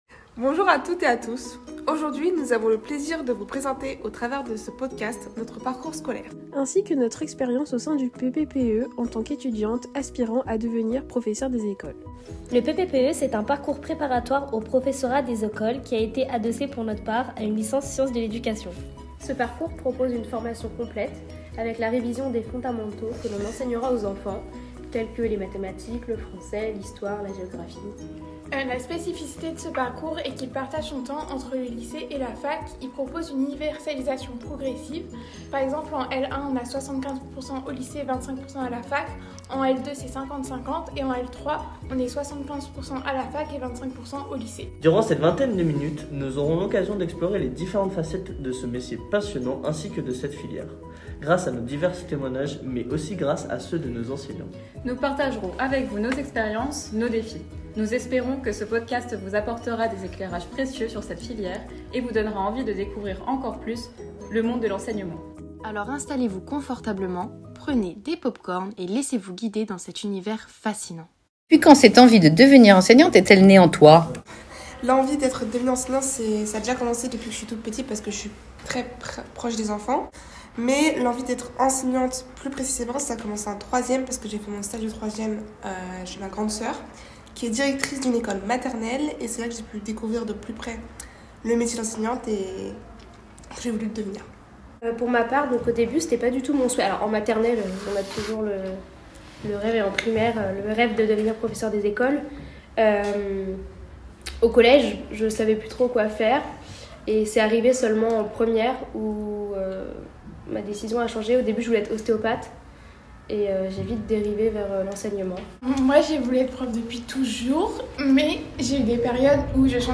Un podcast pour tout savoir et écouter les témoignages enthousiastes des étudiants·es en troisième année.